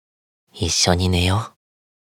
Chat Voice Files